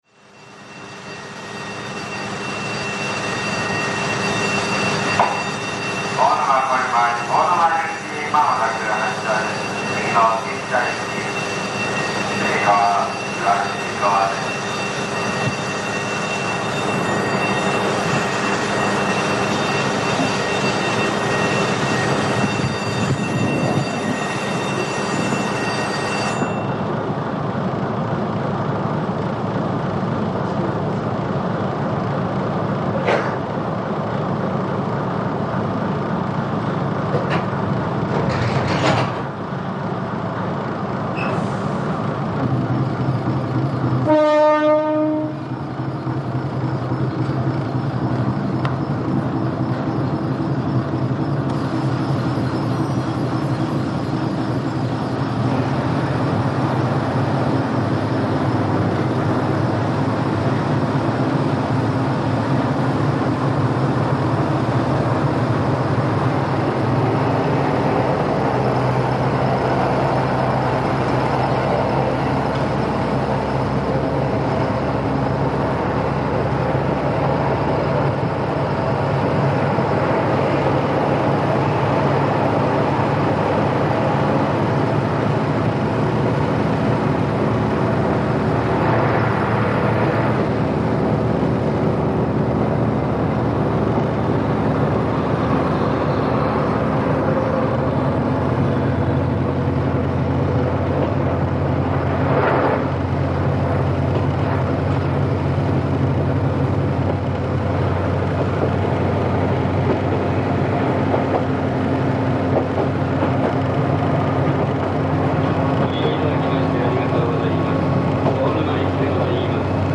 短い区間ですが駒ケ岳山ろくの急こう配を結構喘いで登ります。列車はキハ21の二両連結。
数に終わっています。途中で勾配が急になりエンジンをギアダウン、姫川駅の手前では精いっぱいの馬力で登ります。
hok-kiha212himekawa.mp3